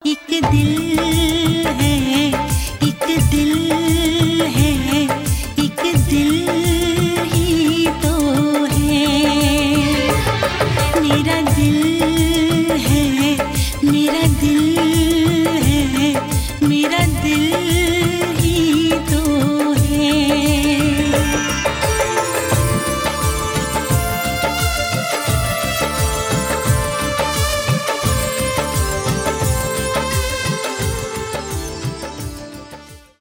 болливуд